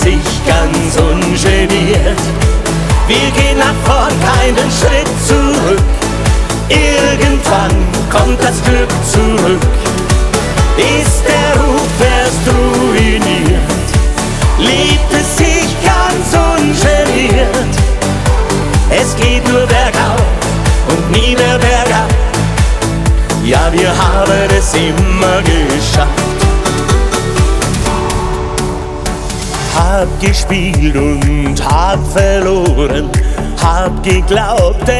# Немецкий поп